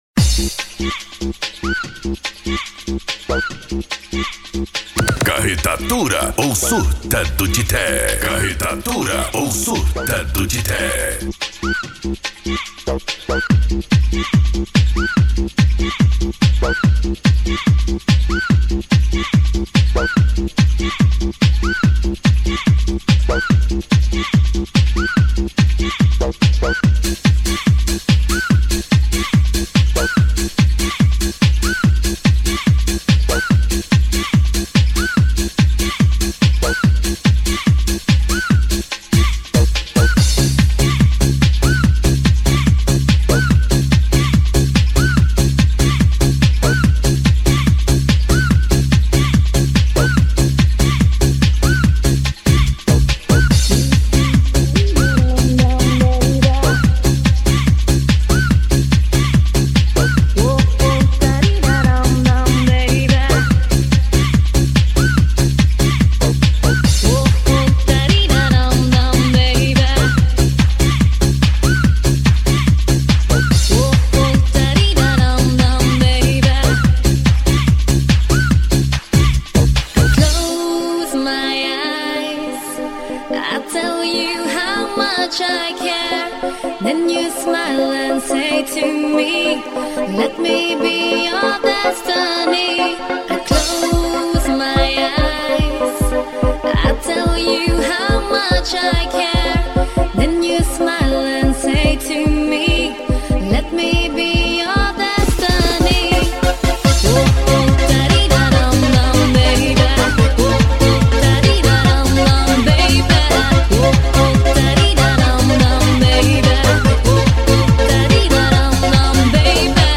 Euro Dance
Funk
Sets Mixados